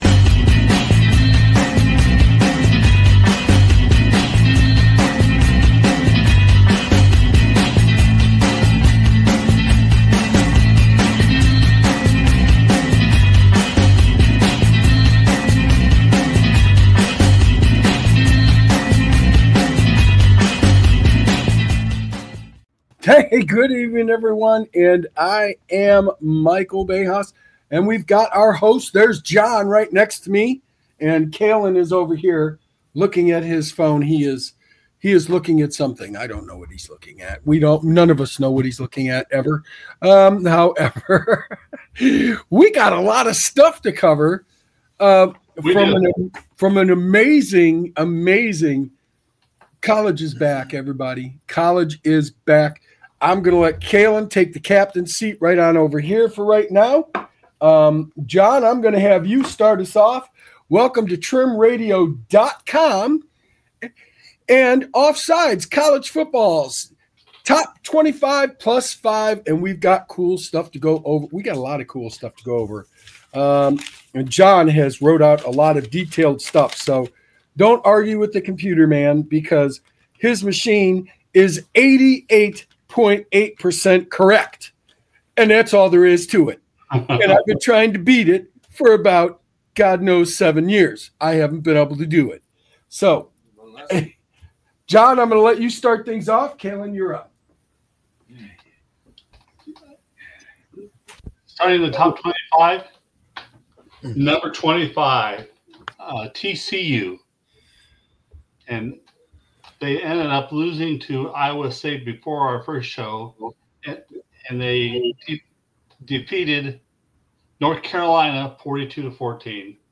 This episode features a fiery debate on why the computer has its own logic, as the hosts try to make sense of the new rankings. They also give their picks for all of the upcoming week's biggest matchups.